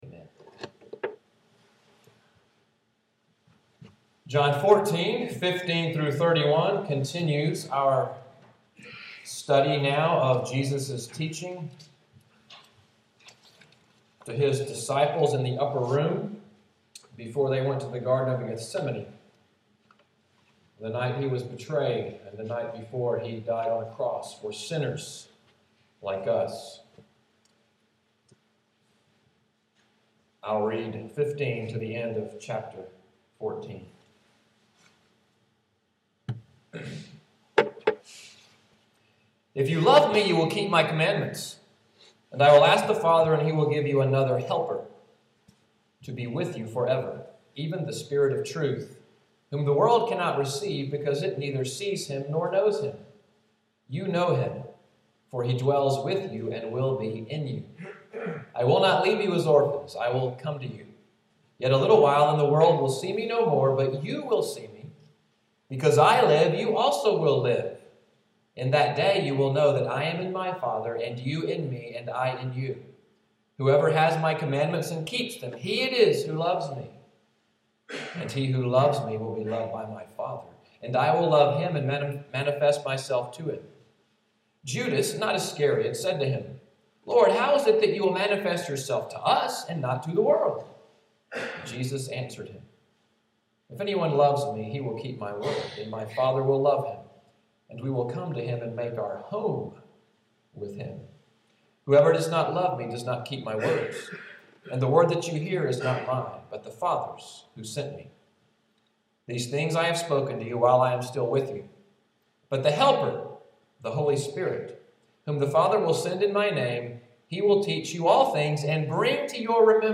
Audio of the sermon, “Another Paraclete,” December 1, 2013